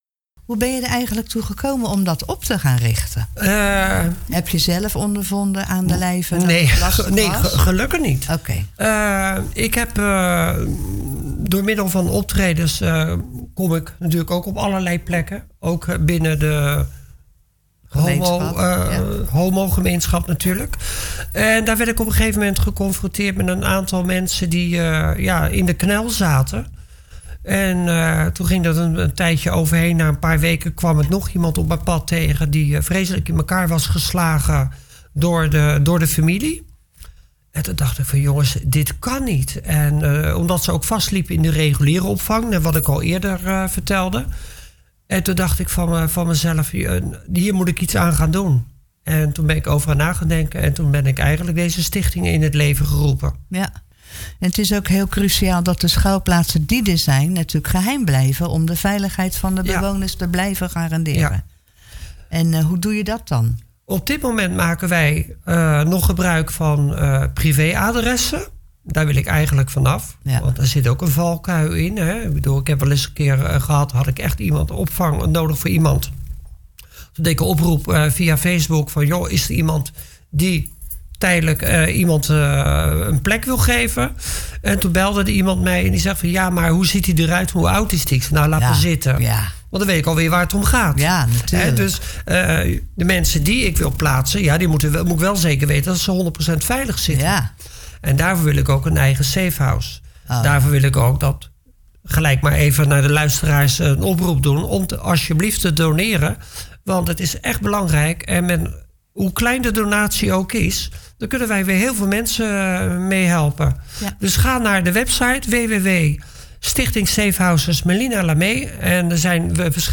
gesprek